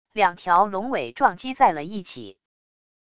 两条龙尾撞击在了一起wav音频